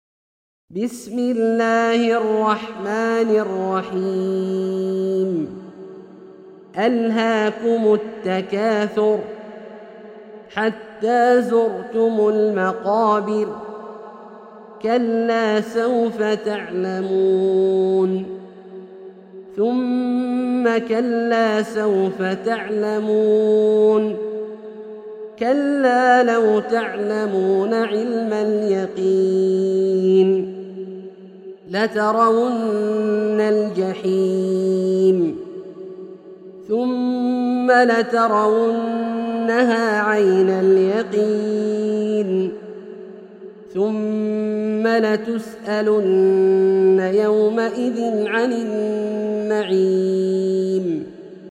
سورة التكاثر - برواية الدوري عن أبي عمرو البصري > مصحف برواية الدوري عن أبي عمرو البصري > المصحف - تلاوات عبدالله الجهني